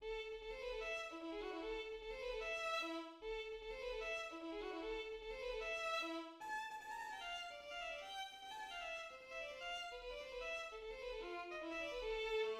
Theme
The caprice, in the key of A minor, consists of a theme, 11 variations, and a finale.
Paganini_caprice24_theme.mid.mp3